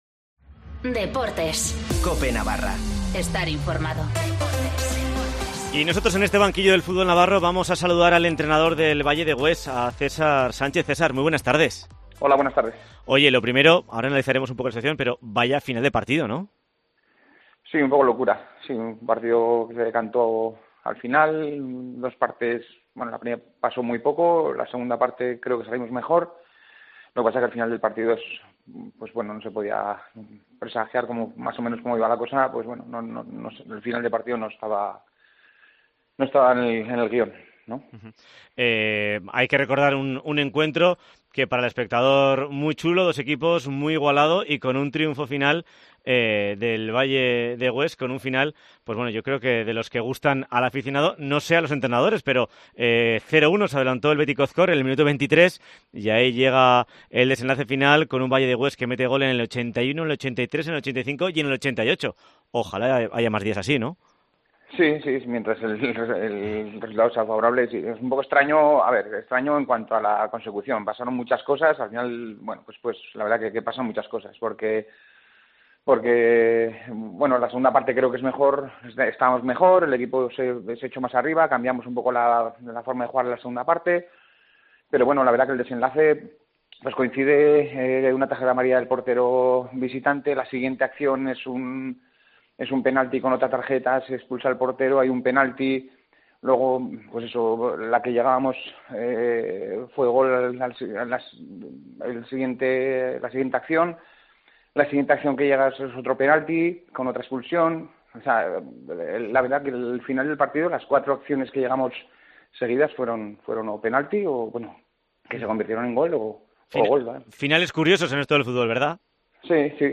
Bajo el patrocinio del Desguaces La Cabaña escuchamos a protagonistas de nuestros equipos: presidentes, entrenadores y jugadores.